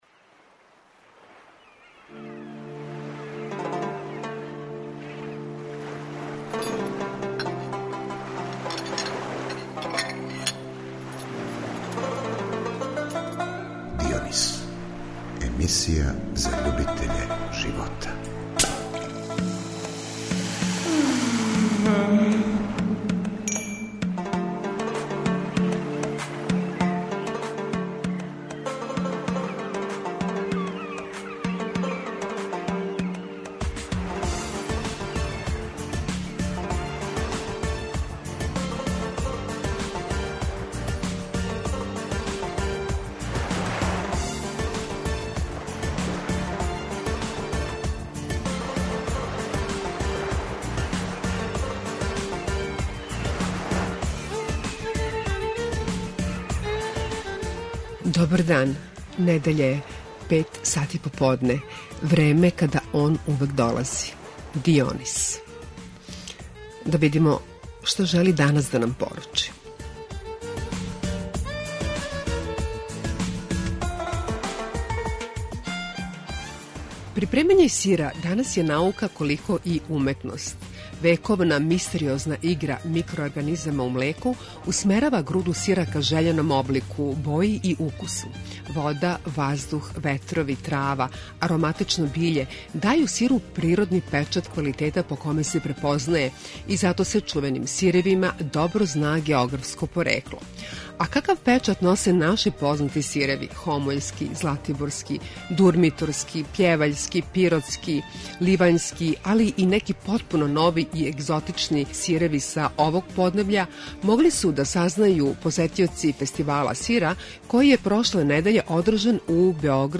А какав печат носе наши познати сиреви: хомољски, златиборски, дурмиторски, пљеваљски, пиротски, сомборски, ливањски, али и неки потпуно нови и егзотични сиреви - могли су да сазнају посетиоци Фестивала сира, који је прошле недеље одржан у Београду, у Миксерхаусу. И ми смо били тамо...